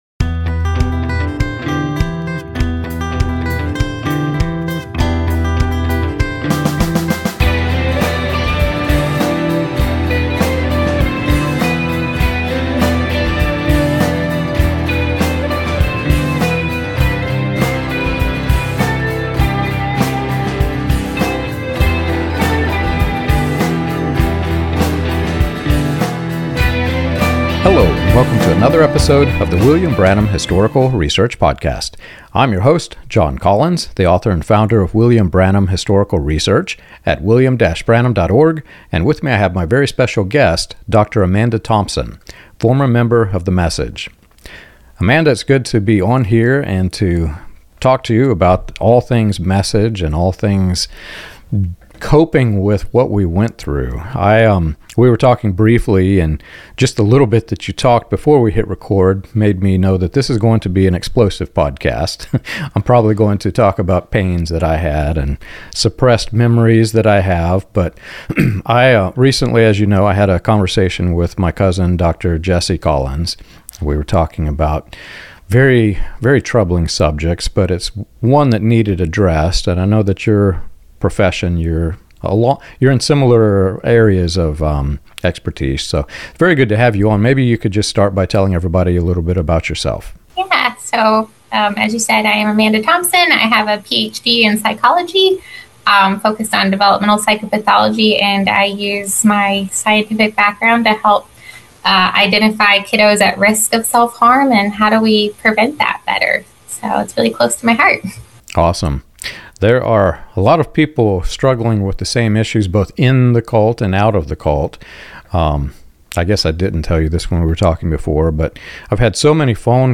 This conversation is for anyone wrestling with religious trauma, lingering fear about prophecies, or the question of whether a healthy, ordinary life is still possible after spiritual abuse.